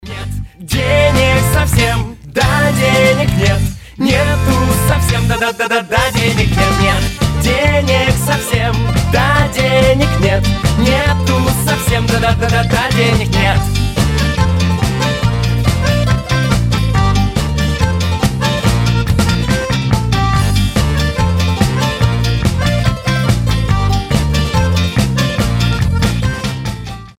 • Качество: 320, Stereo
веселые
аккордеон
инструментальные
Гармошка